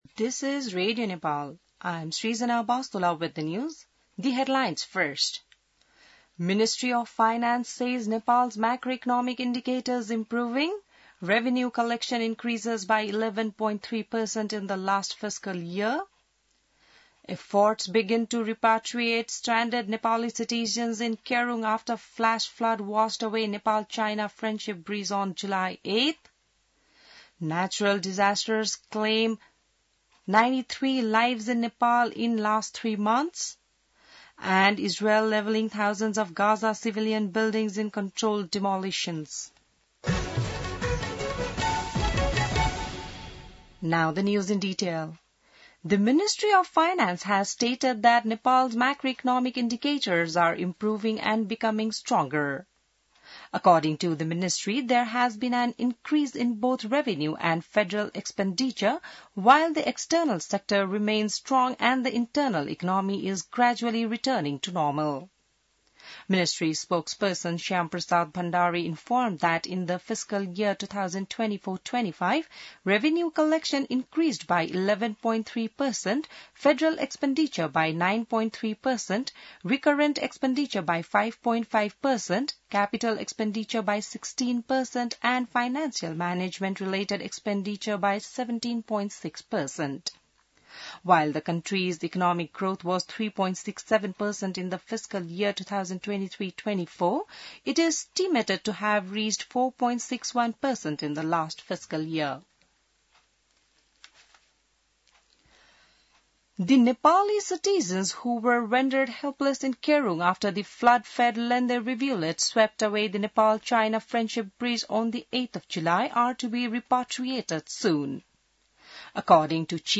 बिहान ८ बजेको अङ्ग्रेजी समाचार : २ साउन , २०८२